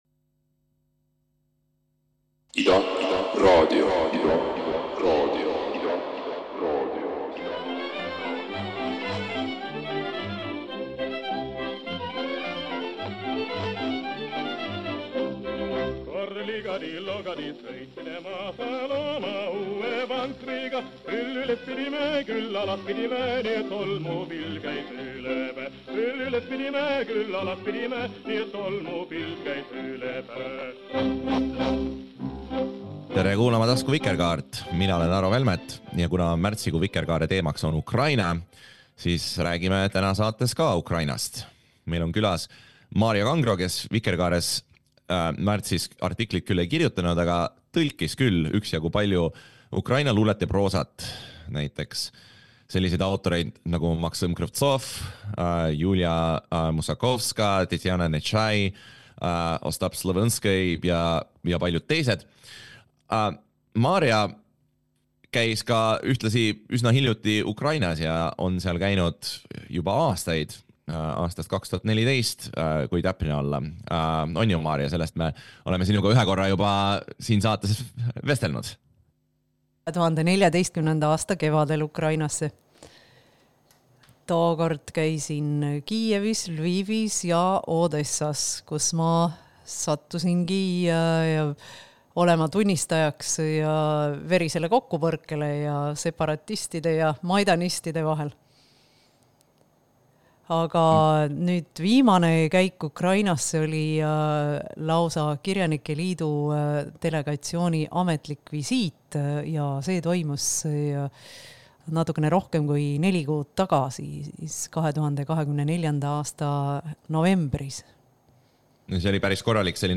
vestlevad oma hiljutistest Ukraina-külastustest, kultuurirahva toimetulekust sõjaajal, suundadest uuemas Ukraina kirjanduses ja muust aktuaalsest.